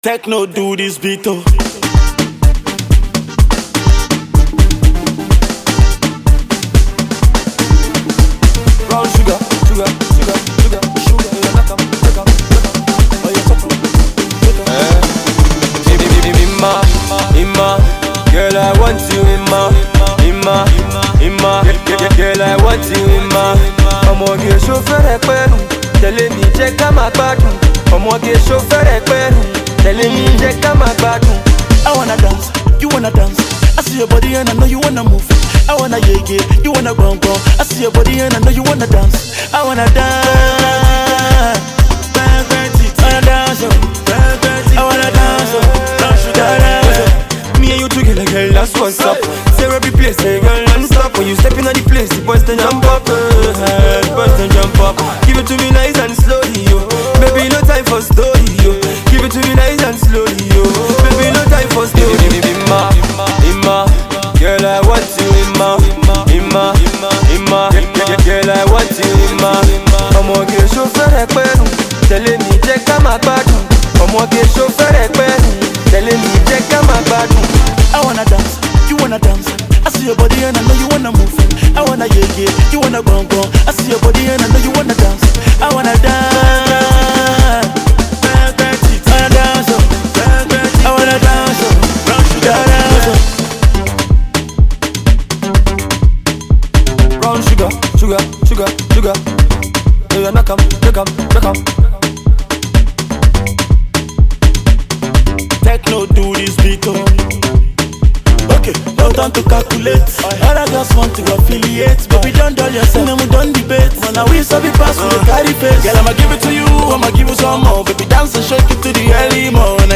While the catchy crooning Afro Pop/dancehall act
the clubs are goning to love this